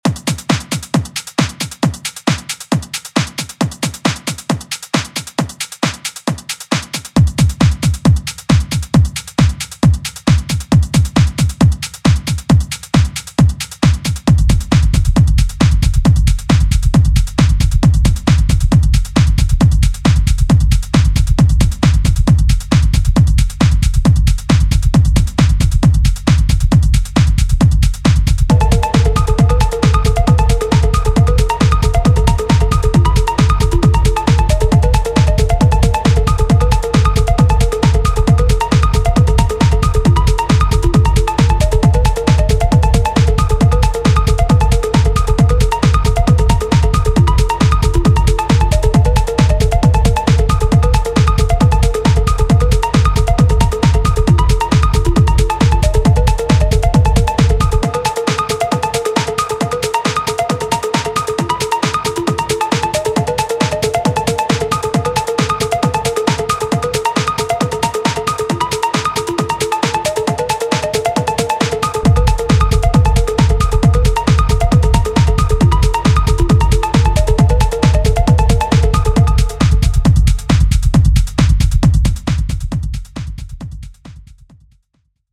tbh, I just had my lowend going in which I tried to make a relatively tight kick hit hard and seem huge:
both, bassline and kick alone are not oversized or something but together they seem like an idiot put faders to max.